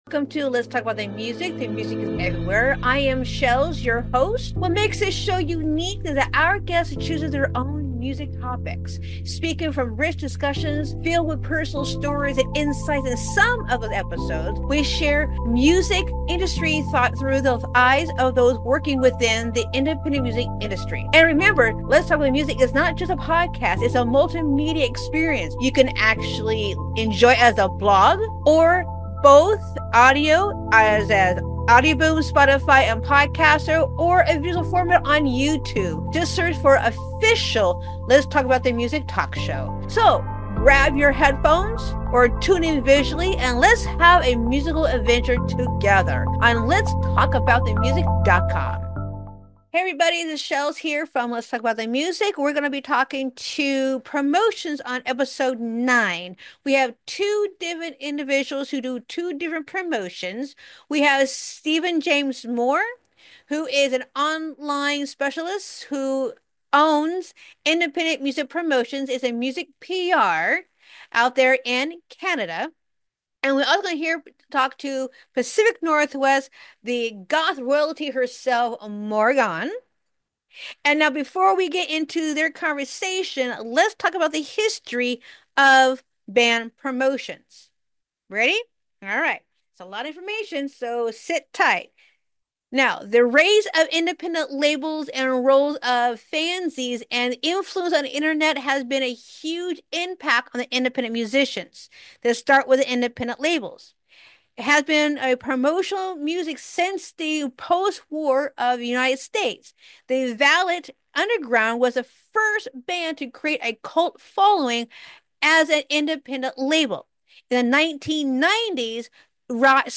Lets Talk About The Music, Talk Show / LTATM Ep 9 | The Evolution of Music Promotions